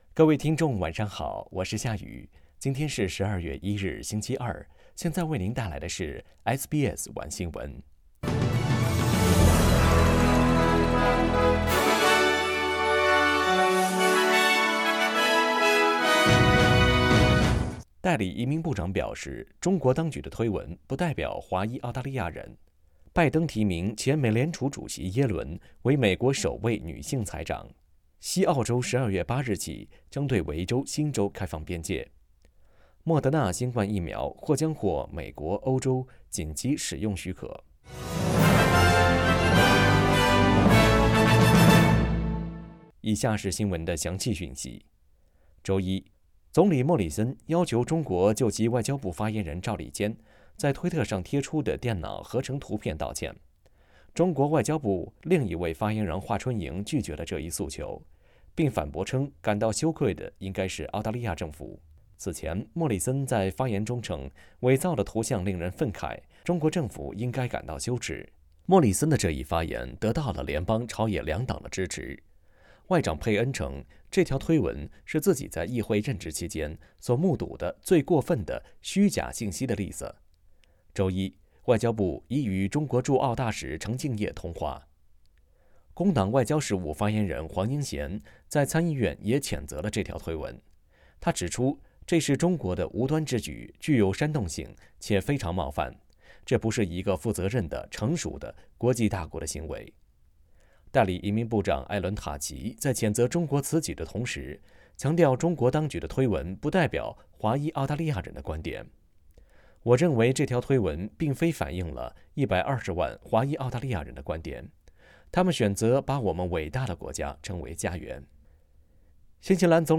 SBS晚新聞（12月1日）
mandarin_news_1201.mp3